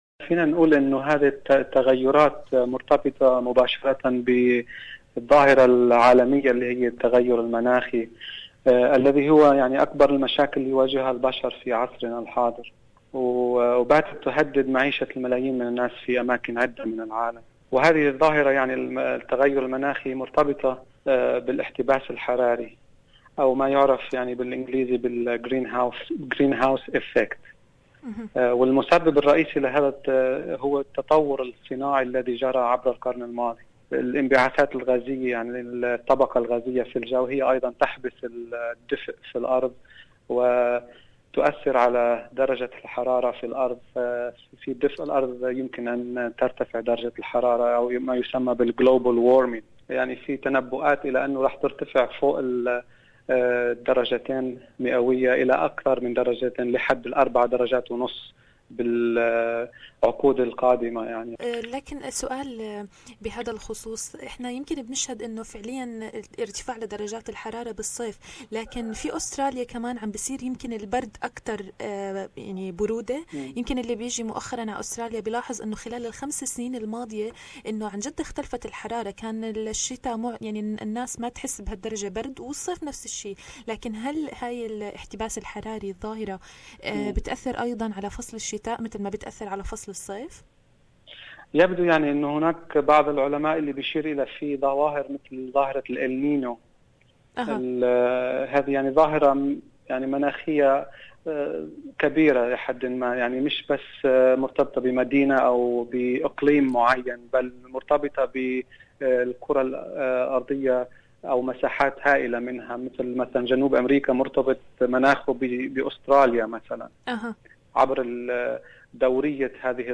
المقابلة التالية